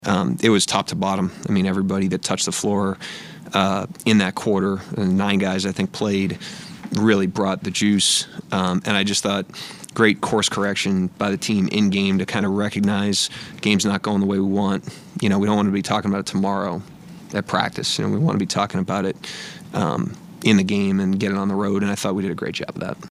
Thunder head man Mark Daignault talks about the third quarter two nights ago.